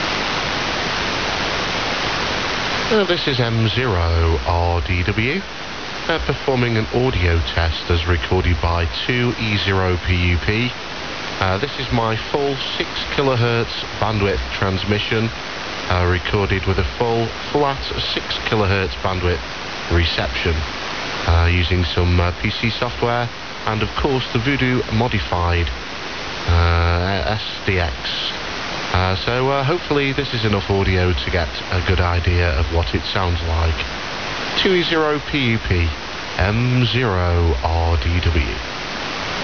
Recorded using Kenwood VM TS-950SDX @ 0 - 6000Hz
To appreciate the full fidelity of the enhanced frequency response.